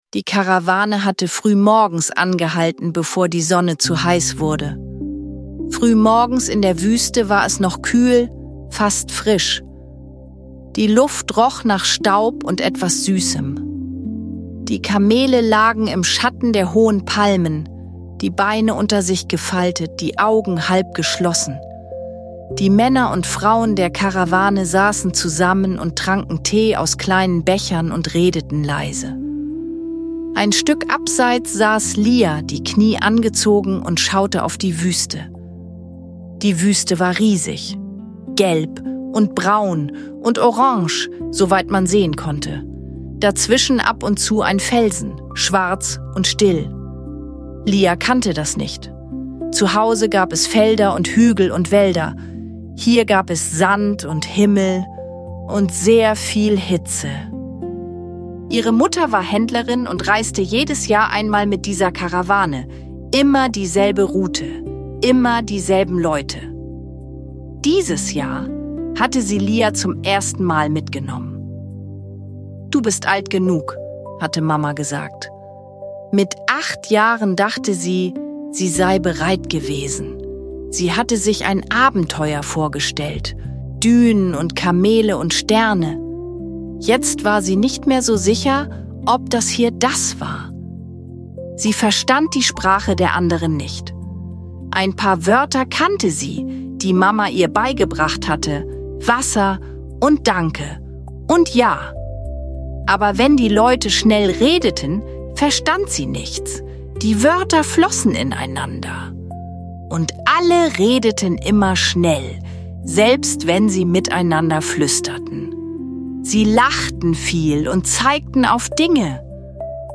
Lia und das leise Lied der Wüste – Gute Nacht Geschichte für Kinder ~ Gute Nacht Geschichten für Kinder zum Einschlafen – Sylwias Ausmalwelt Podcast
Die warme Abendstimmung, das sanfte Schaukeln der Kamele und die Stimmen der Karawane schaffen eine beruhigende Atmosphäre. Eine Geschichte über Vertrauen, kleine Fortschritte und das Gefühl, dazuzugehören.